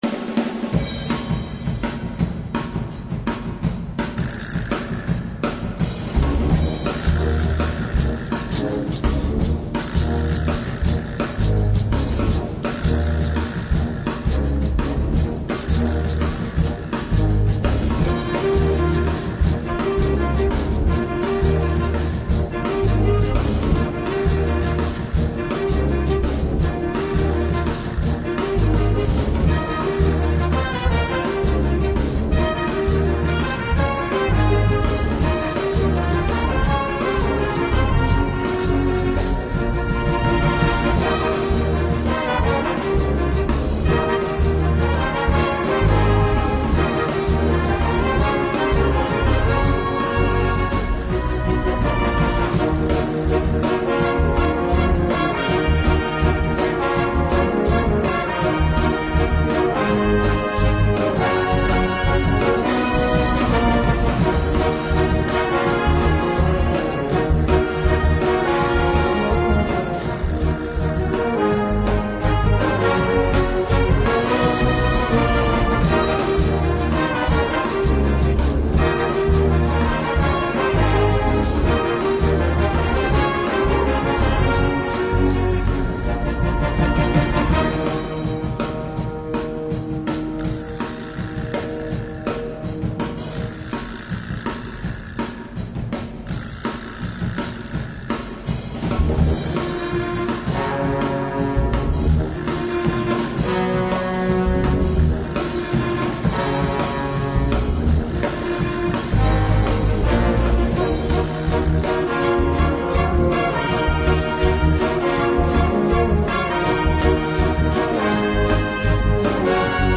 Gattung: Pop-Rock
Besetzung: Blasorchester